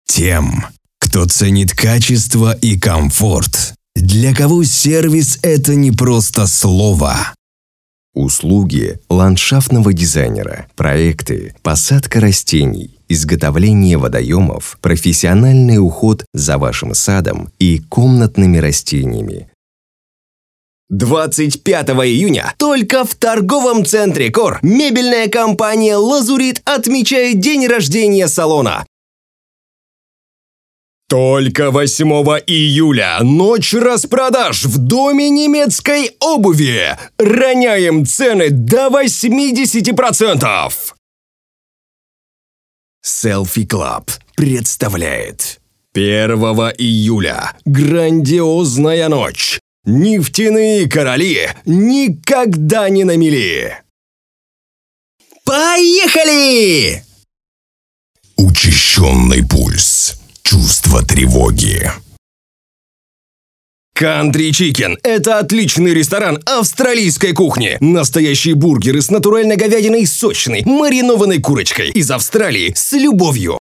Подача голоса от «строго», до голоса «аля-RECORD» или «комического».